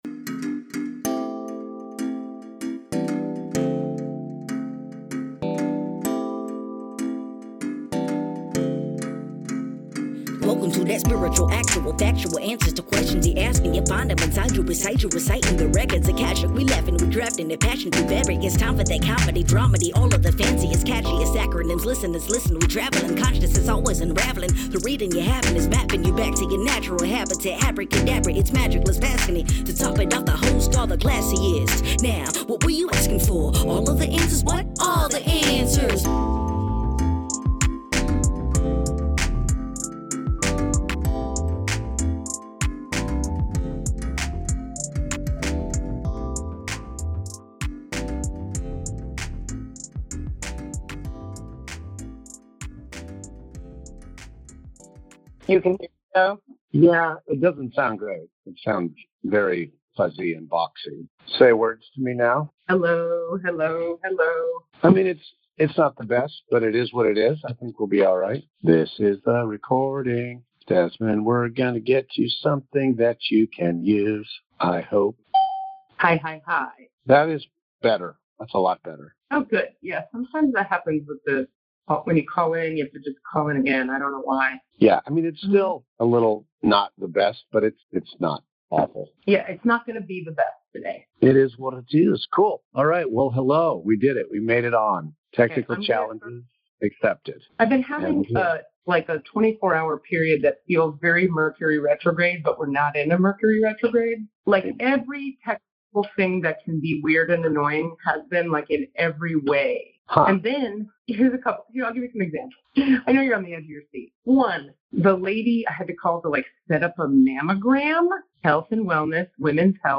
dealing with sub-optimal recording circumstances